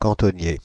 Prononciation
Prononciation Paris: IPA: [kɑ̃.tɔ.nje] France (Île-de-France): IPA: /kɑ̃.tɔ.nje/ Le mot recherché trouvé avec ces langues de source: français Les traductions n’ont pas été trouvées pour la langue de destination choisie.